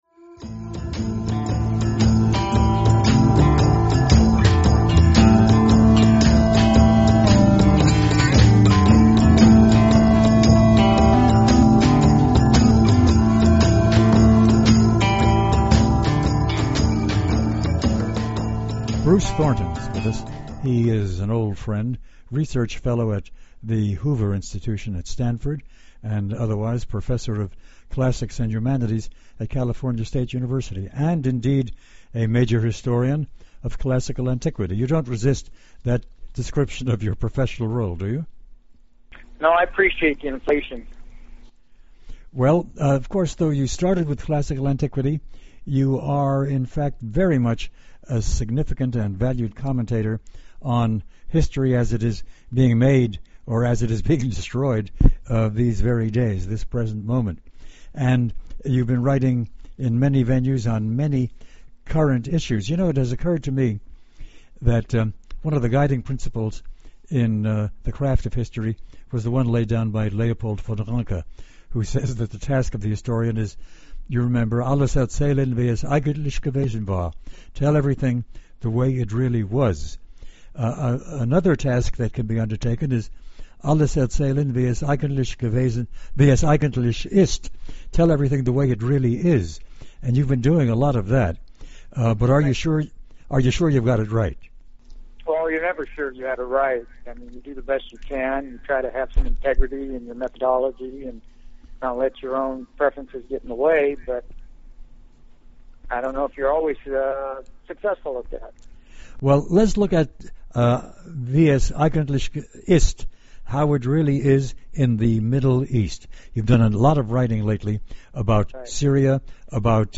Milt Rosenberg is a daily podcast that features provocative and thought provoking discussion centered on the world of ideas.